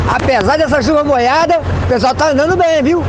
Play, download and share Chuva molhada original sound button!!!!
chuva-molhada.mp3